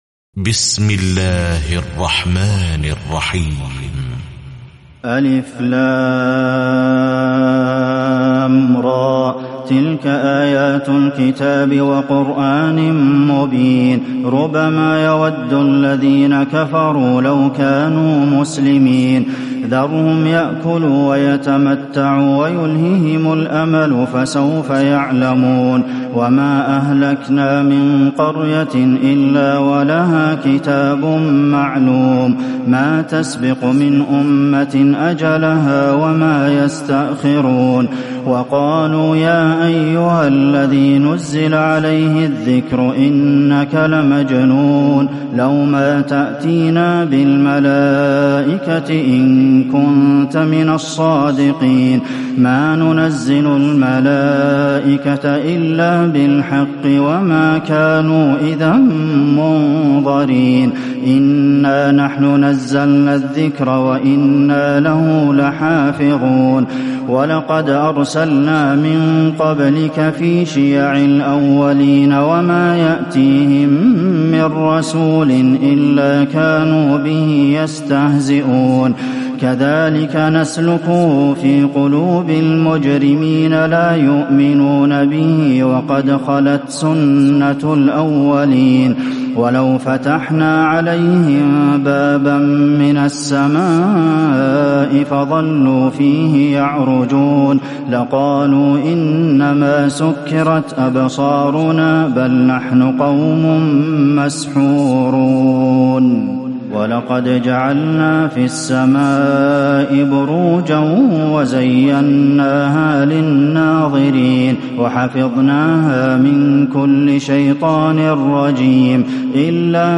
تراويح الليلة الثالثة عشر رمضان 1438هـ من سورتي الحجر كاملة و النحل (1-29) Taraweeh 13 st night Ramadan 1438H from Surah Al-Hijr and An-Nahl > تراويح الحرم النبوي عام 1438 🕌 > التراويح - تلاوات الحرمين